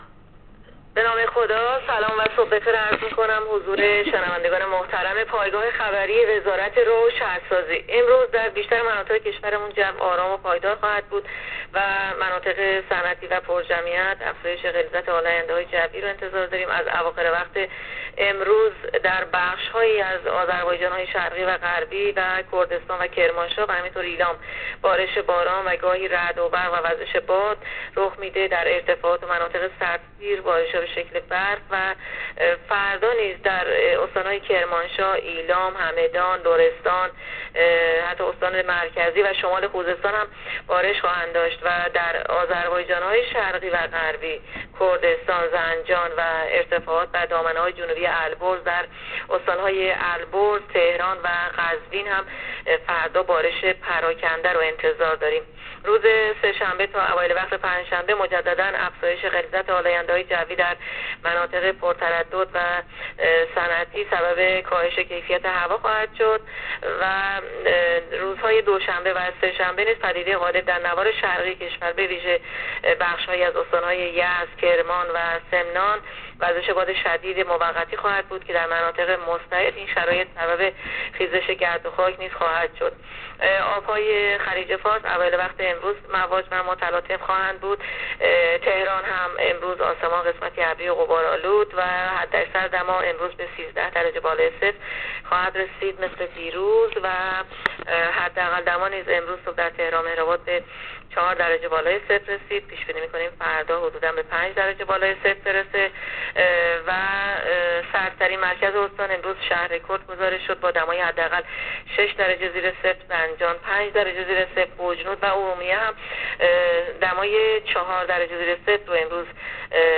کارشناس سازمان هواشناسی کشور در گفت‌وگو با رادیو اینترنتی وزارت راه‌وشهرسازی، آخرین وضعیت آب‌و‌هوای کشور را تشریح کرد.
گزارش رادیو اینترنتی از آخرین وضعیت آب‌‌و‌‌‌هوای چهاردهم آذر؛